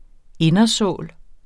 Udtale [ ˈenʌ- ]